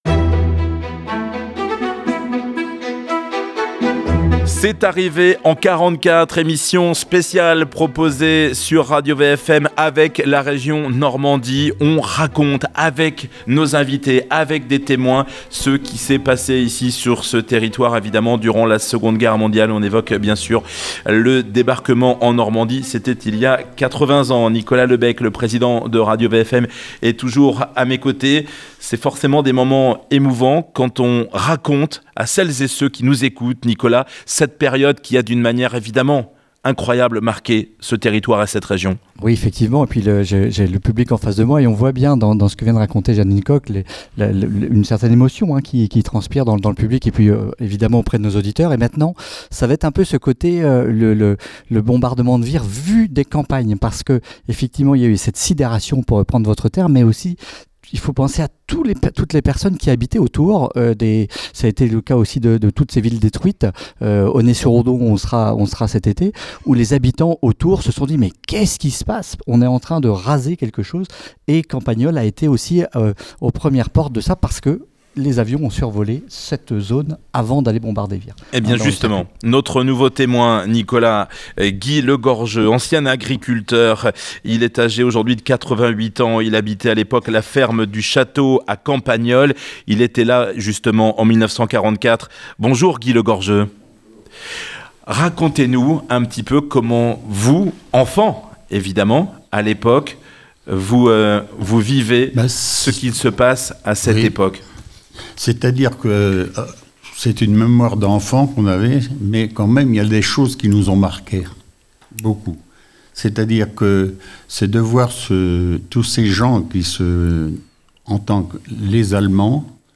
Émission exceptionnelle diffusée Samedi 15 Juin à Campagnolles à 10 h 15. Témoignages, récits de la libération du Bocage.